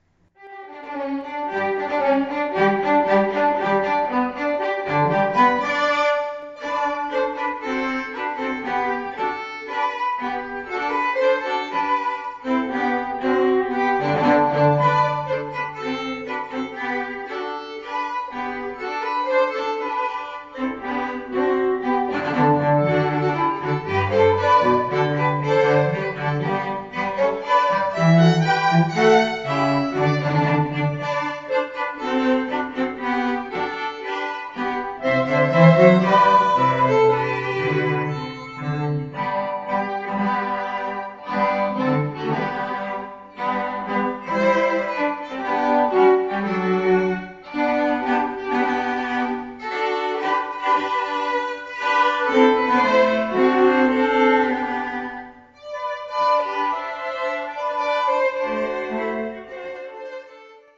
Santa's Classical Christmas: (Qt.)